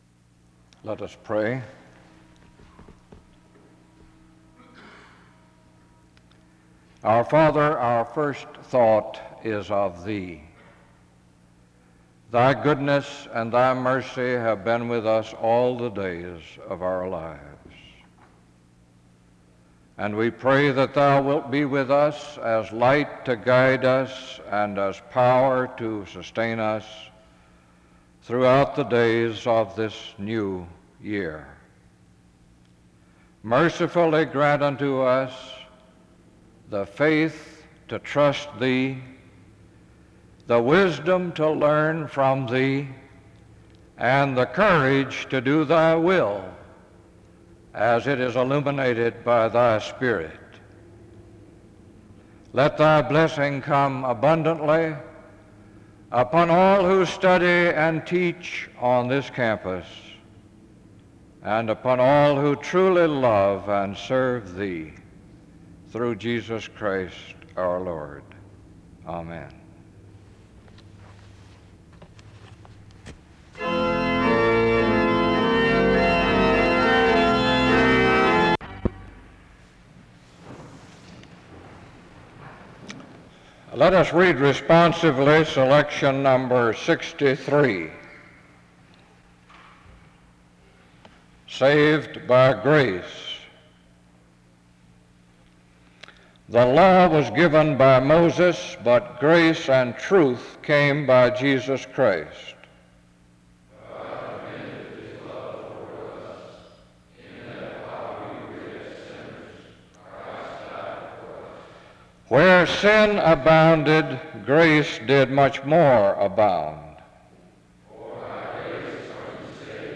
The service begins with a prayer (0:00-1:20). After which, the speaker reads a responsive reading (1:21-2:56).
He closes in prayer (24:15-25:08).